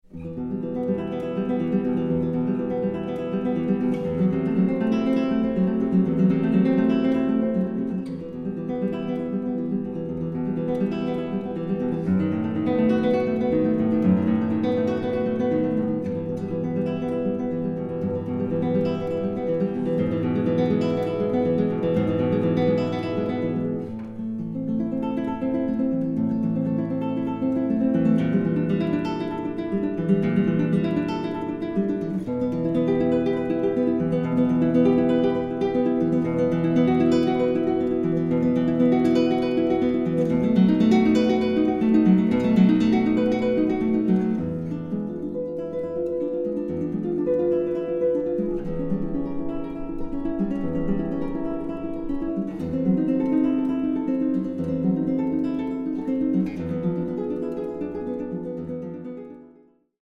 Guitar
richly colored and precisely articulated guitar sound